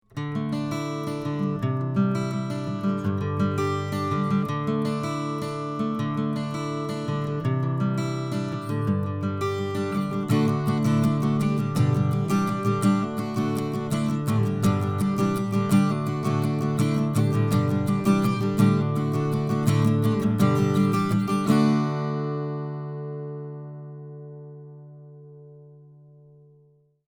These mics sound wonderful, and
here is a clip of a Collings acoustic from mic #34024, run through a Gordon pre and a Lavry D/A with no EQ (MP3).
KM84lastriff.mp3